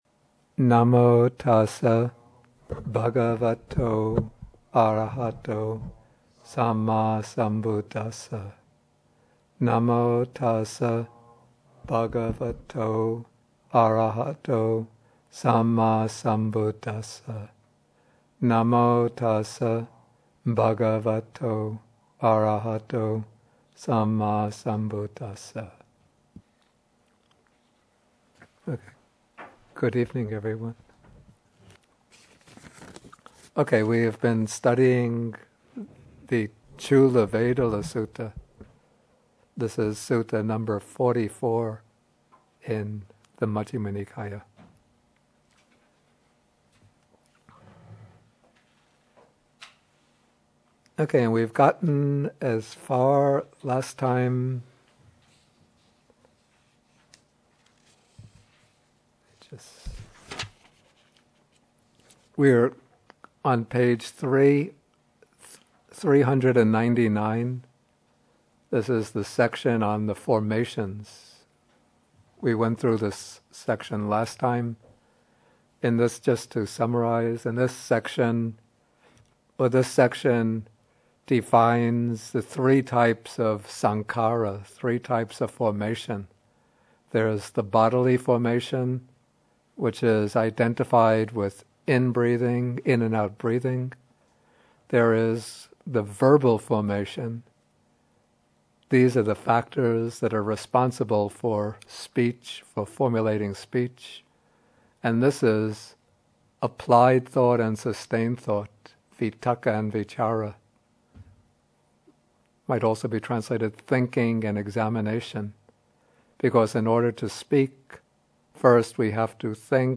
MN44, Bhikkhu Bodhi at Bodhi Monastery (lectures 133 to 135)